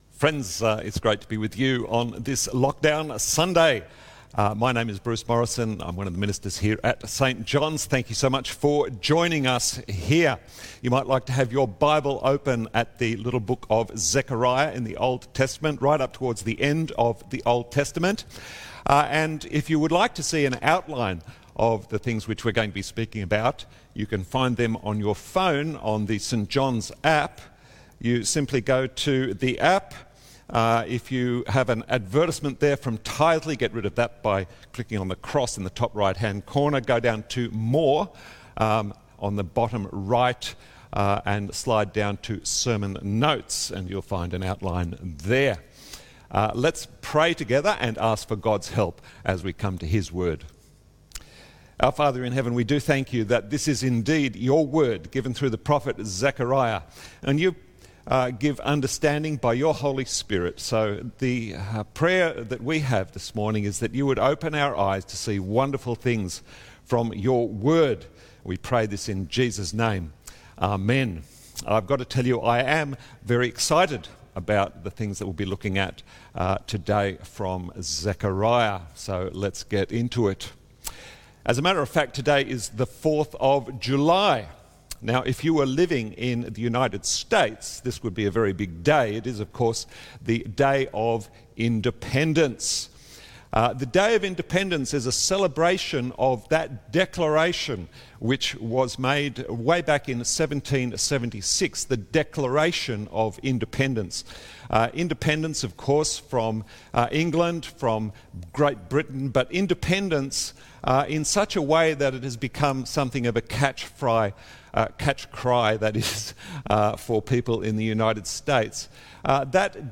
Zechariah 12:1-9, 14:6-21 Sunday sermon